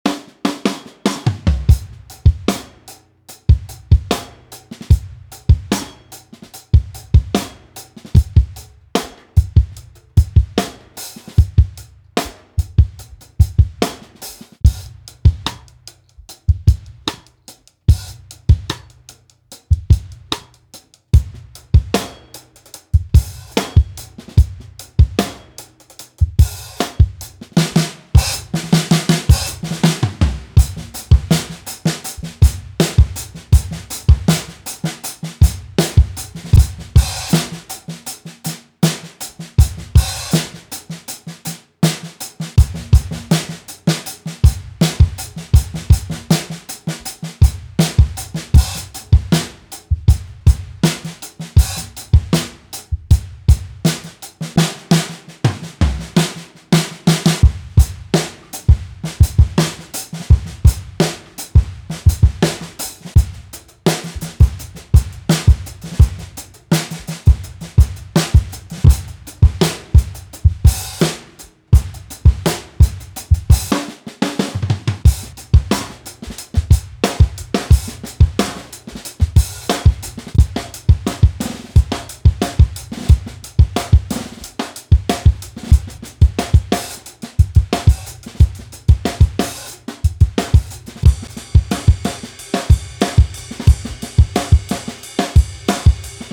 This premium loop pack features 24 meticulously recorded acoustic drum loops and 16 dynamic drum fills, designed to bring organic warmth and rhythmic energy to your music.
16 Drum Fills: Add flair and excitement to your transitions with a collection of fills that range from subtle to explosive, ensuring your music remains engaging and fresh.
74 BPM
100 BPM